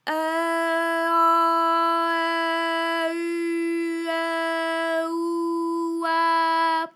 ALYS-DB-001-FRA - First, previously private, UTAU French vocal library of ALYS
eu_au_eu_u_eu_ou_oi.wav